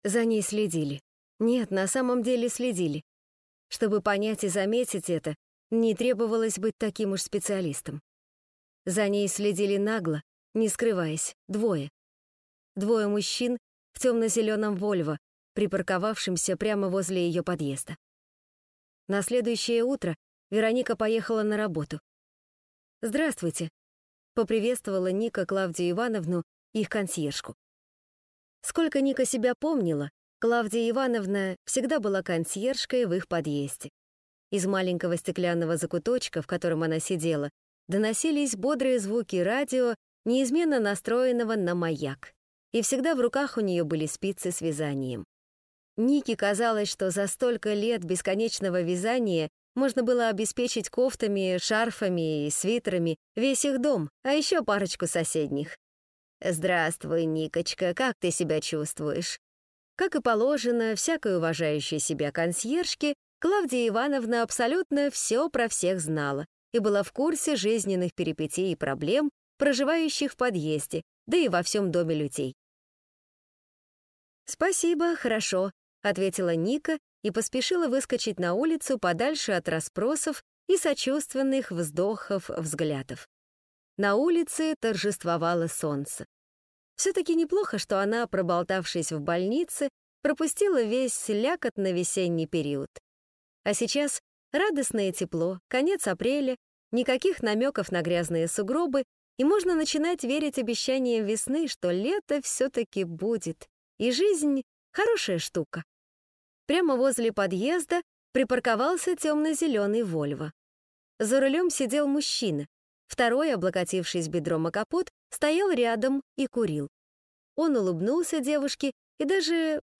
Аудиокнига Любовь со вкусом вишни | Библиотека аудиокниг
Прослушать и бесплатно скачать фрагмент аудиокниги